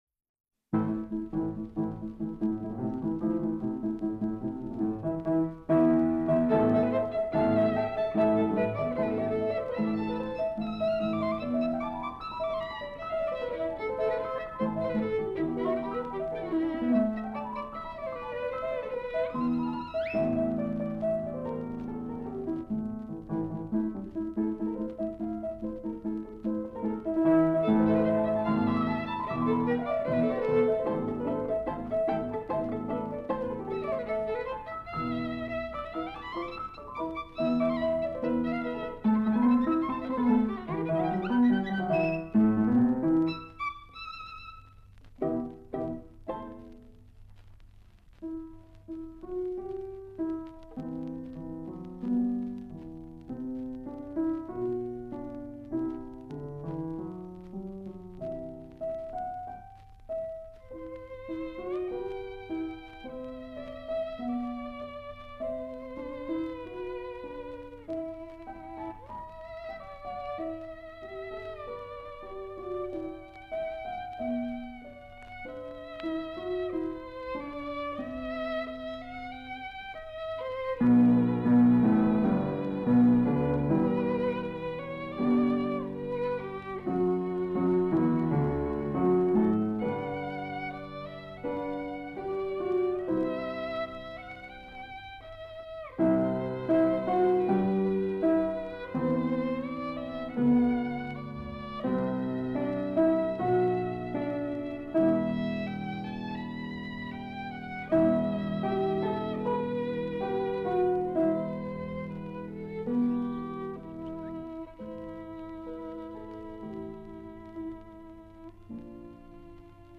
úr hljóðritasafni Ríkisútvarpsins
fyrir fiðlu og píanó (1944)